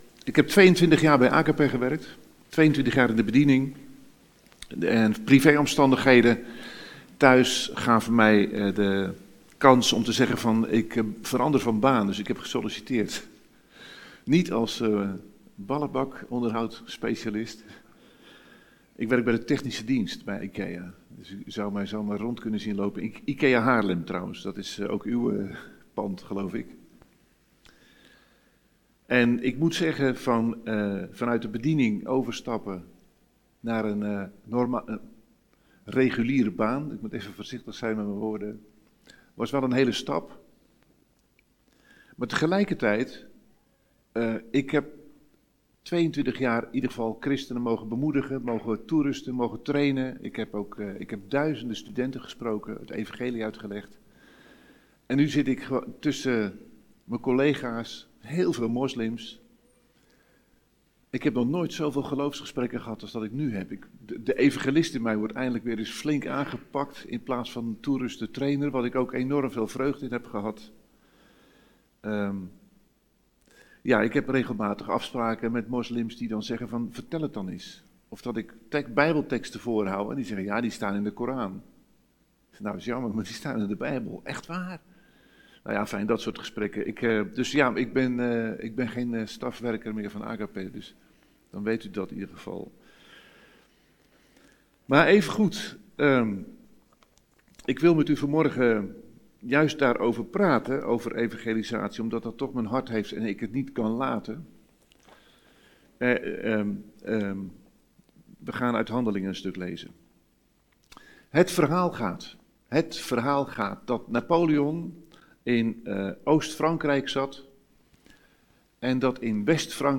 Geplaatst in Preken